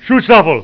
SS_Soldier_toooch_double.wav